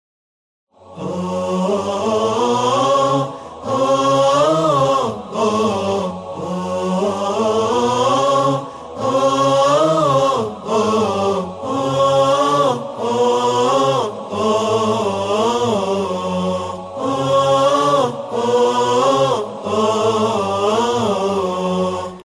وکال مذهبی 10
وکال کوتاه جهت سوئیچ مراسمات و مجالس؛ مناسب جهت استفاده در ساخت کلیپ‌های مذهبی و آئینی؛